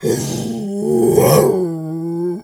pgs/Assets/Audio/Animal_Impersonations/bear_roar_09.wav at master
bear_roar_09.wav